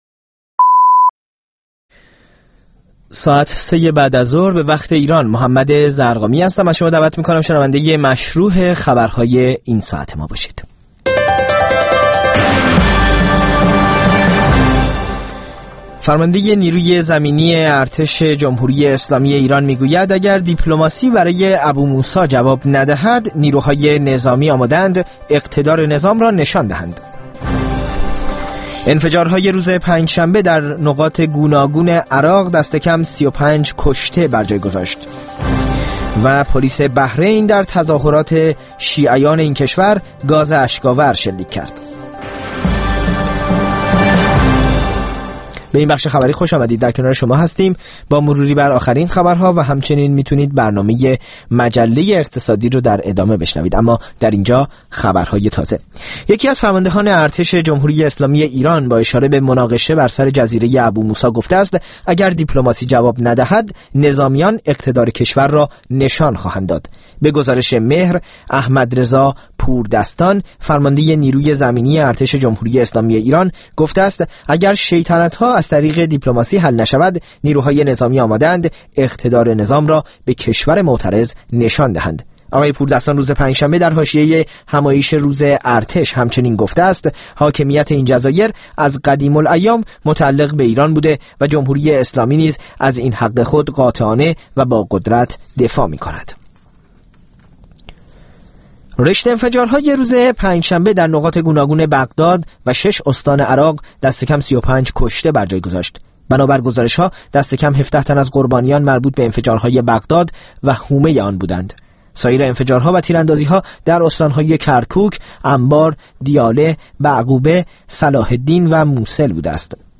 در مجله اقتصادی رویدادهای مهم اقتصادی ایران و جهان در طول هفته با کارشناسان در میان گذاشته و بررسی می‌شود.